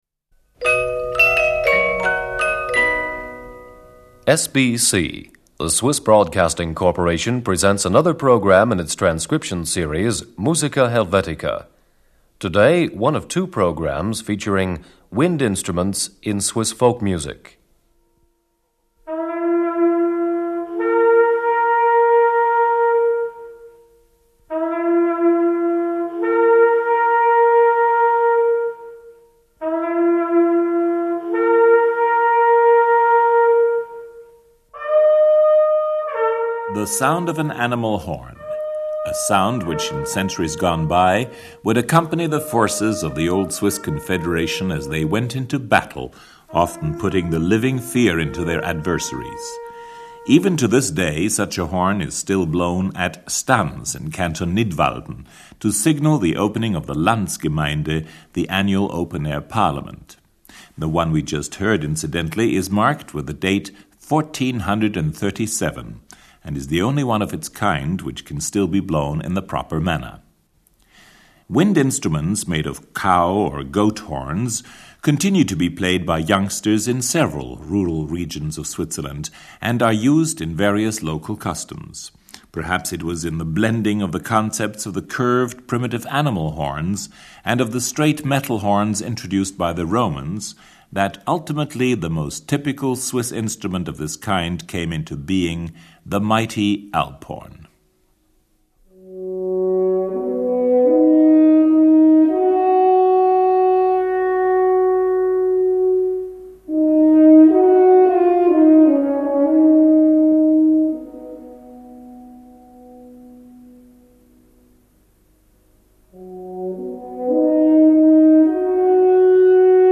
Swiss Folk Instruments. Wind Instruments (II). The Alphorn.
Alphorn Solo. Traditional herdsman’s air from Ormont.
Alphorn Scale.
Improvised Alphorn Solo.
Alphorn Quartet.
Alphorn and Orchestra.
Alphorn and Organ.